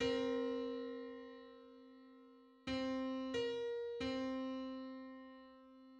Just: 231/128 = 1022.10 cents.
Public domain Public domain false false This media depicts a musical interval outside of a specific musical context.
Two-hundred-thirty-first_harmonic_on_C.mid.mp3